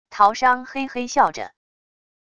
陶商嘿嘿笑着wav音频生成系统WAV Audio Player